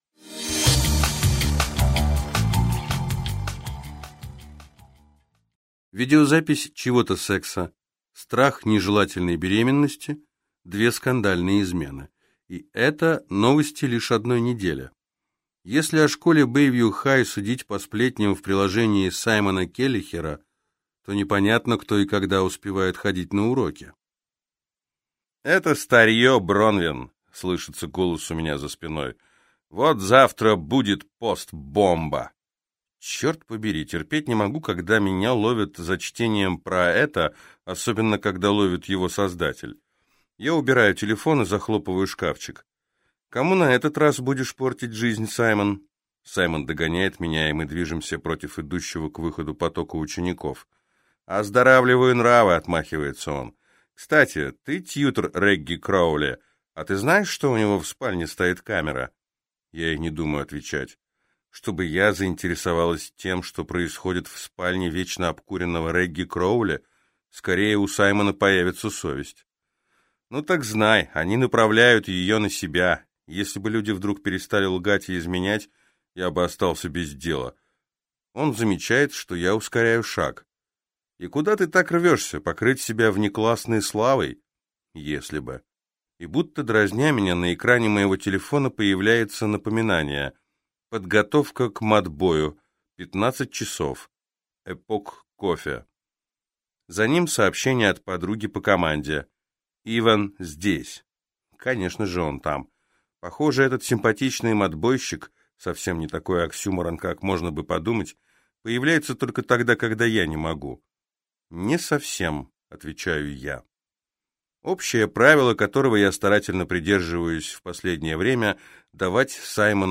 Аудиокнига Один из нас лжет | Библиотека аудиокниг